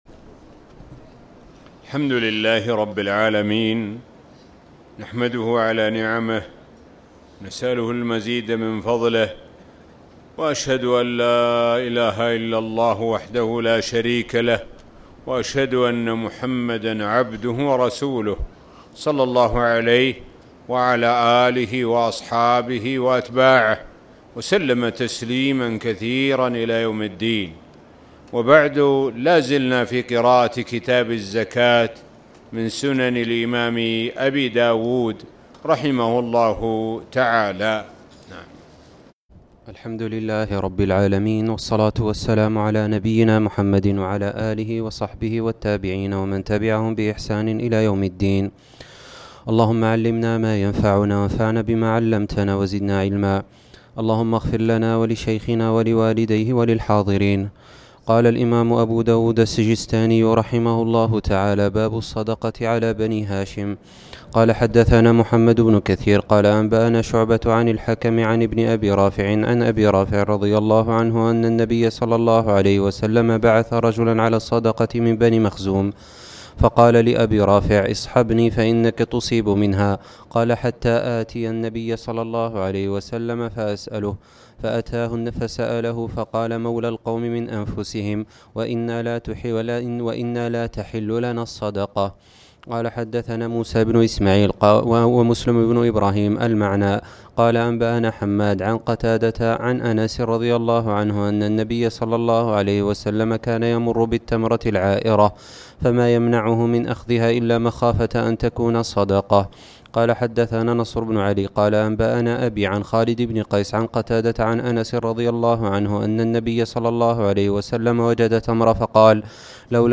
تاريخ النشر ١٦ رمضان ١٤٣٨ هـ المكان: المسجد الحرام الشيخ: معالي الشيخ د. سعد بن ناصر الشثري معالي الشيخ د. سعد بن ناصر الشثري كتاب الزكاة-باب الصدقة على بني هاشم